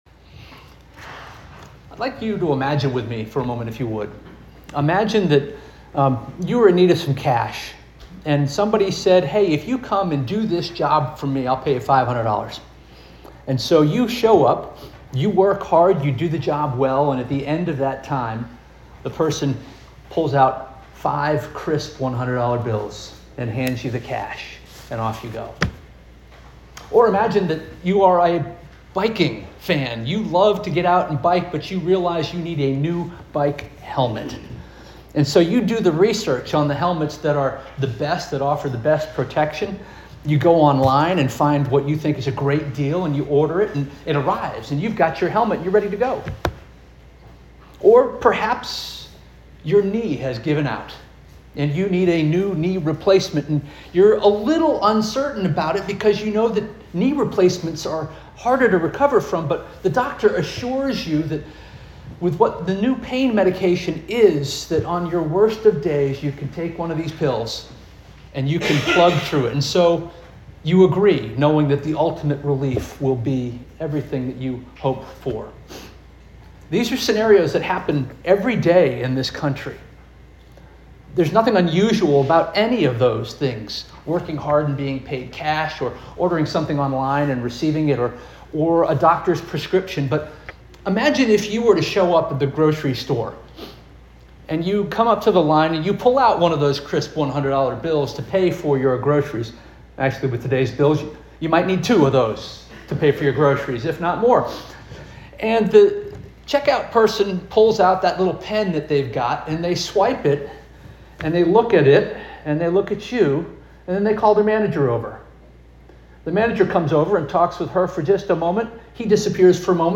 July 20 2025 Sermon